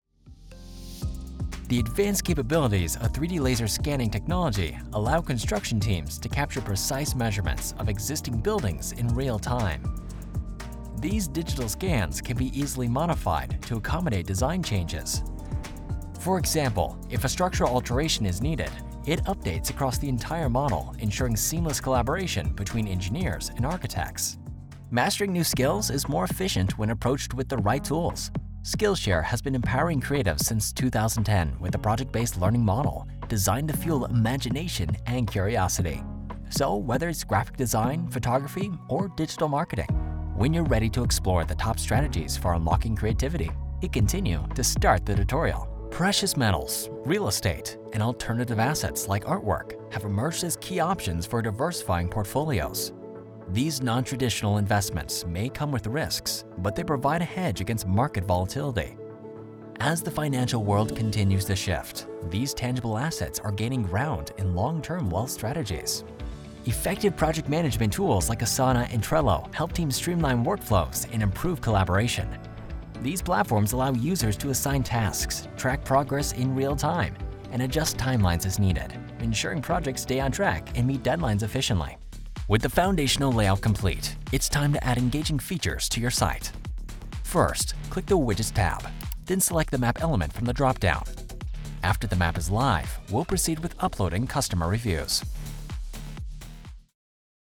I can deliver a sharp and professional read for corporate and branded content or shift into a warm, conversational tone that feels natural and engaging.
Working from a professional home studio allows for fast turnaround times without sacrificing quality.
Male Voice Samples
I specialize in young adult voices, but I always look forward to a character voice or a challenge!